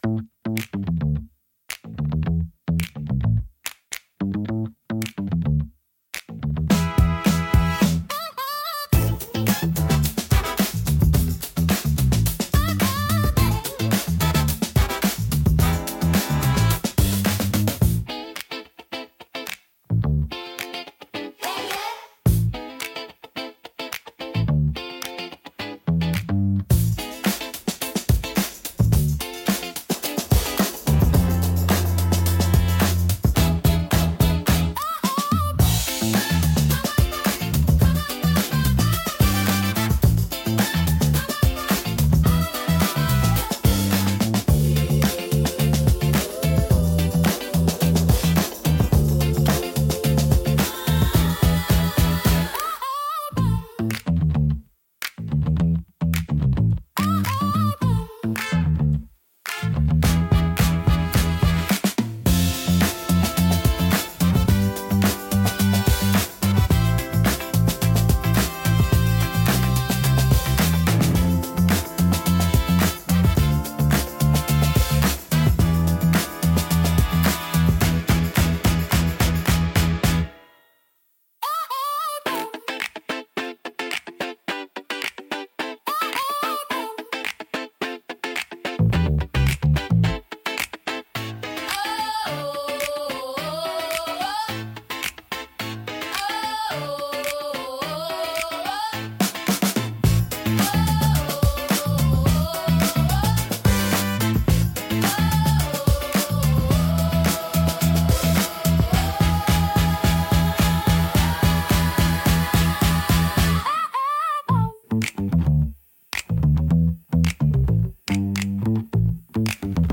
親しみやすいサウンドとポップな雰囲気、明るく感情的な楽曲が多いです。
心に残るハーモニーと温かさが魅力のジャンルです。